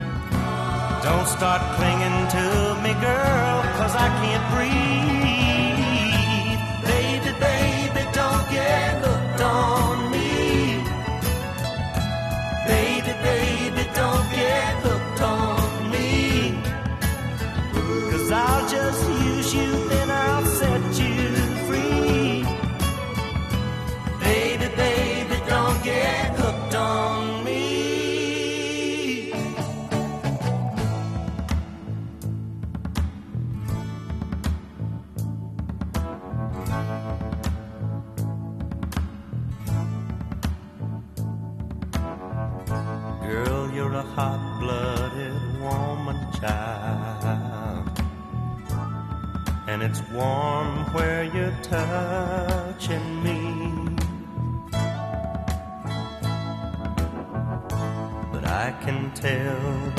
Country pop